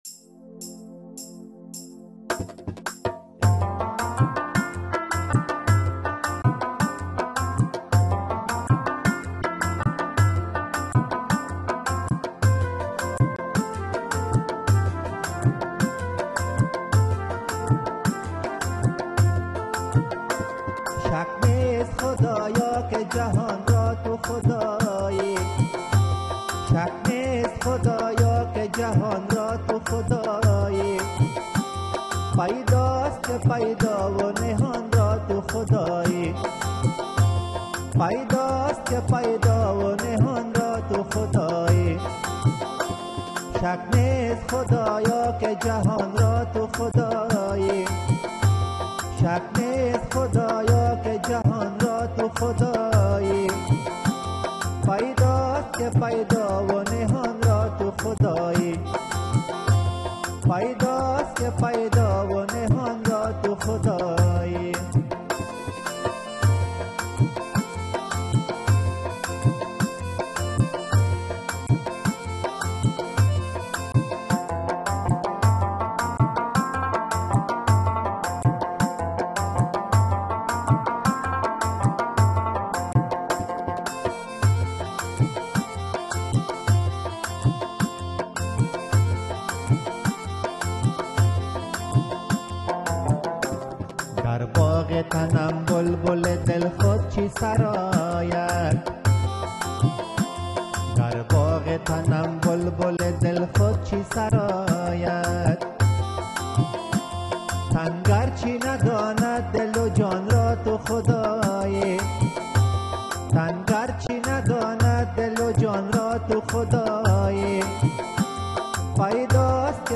متن برنامه / متن سرود